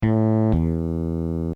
game-over.wav